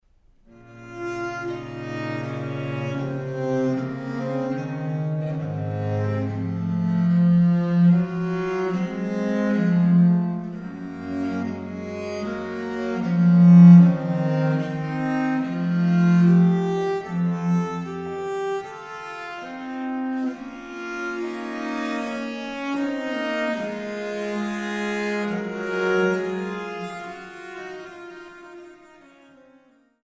Corrente